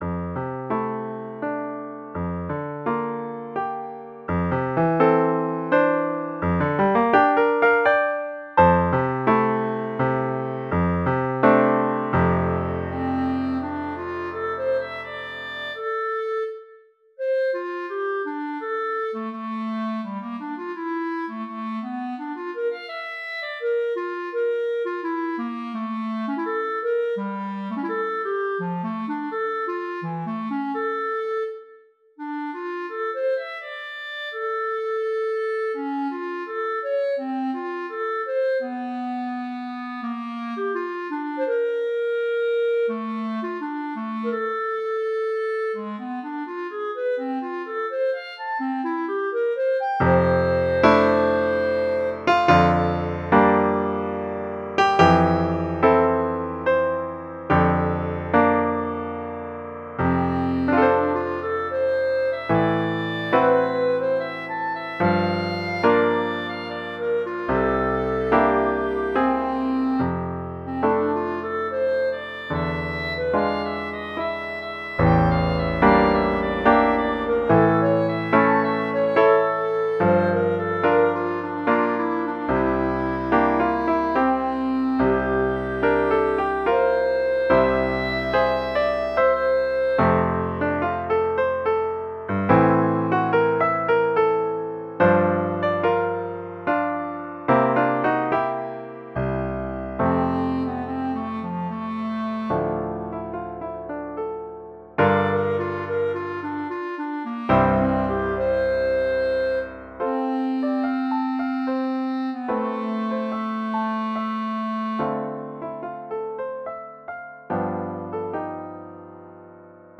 Pour clarinette et piano DEGRE CYCLE 2